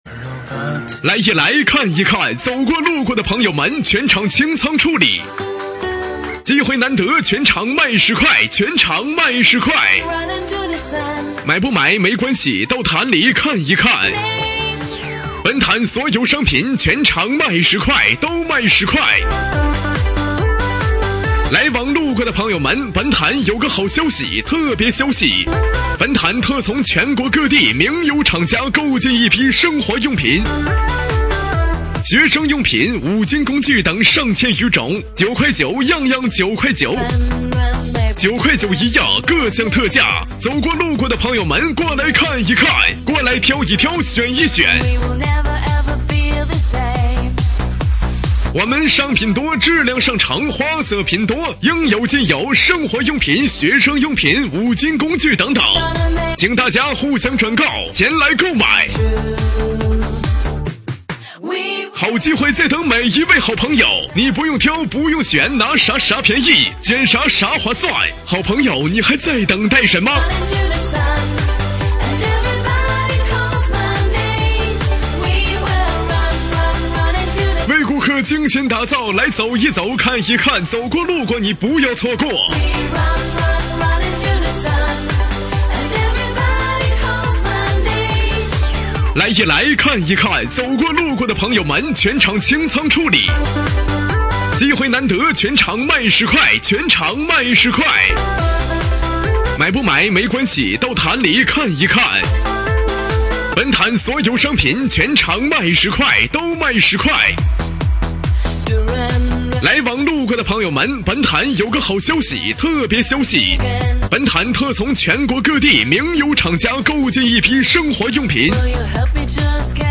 广告喊麦